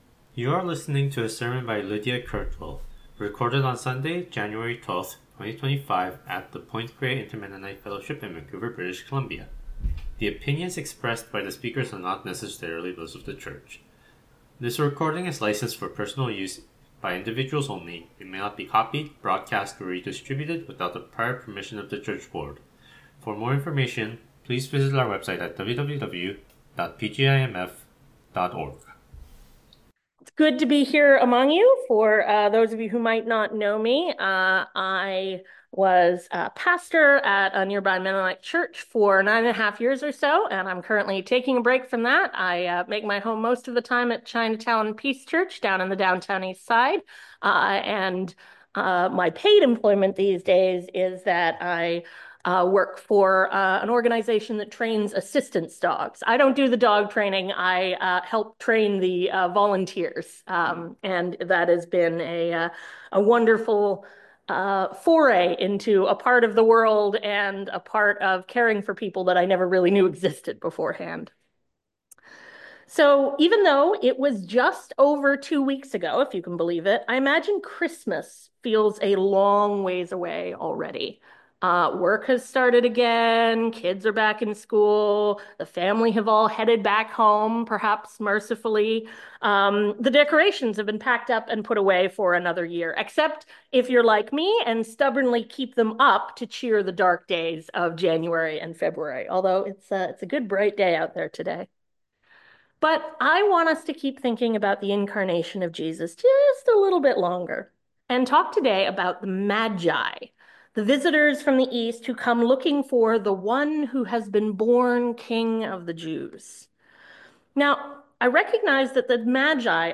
Sermon Recording: Download